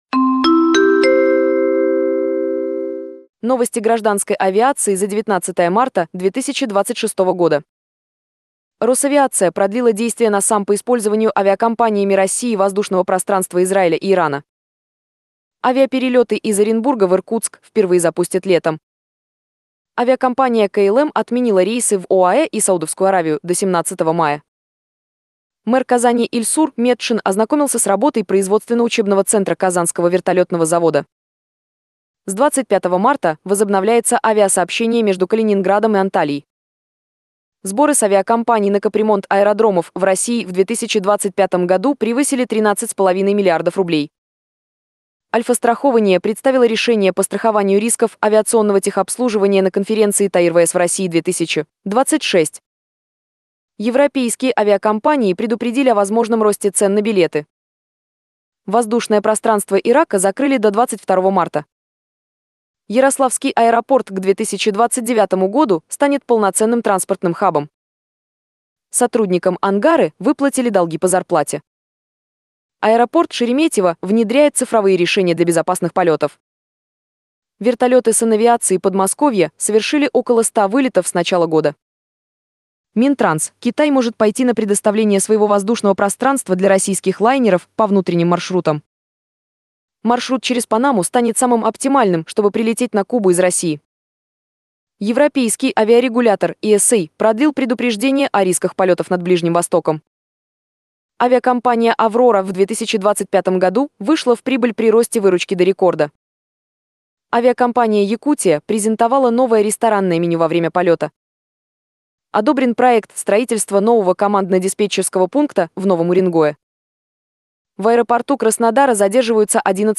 Дайджест авиационных новостей 19 марта 2026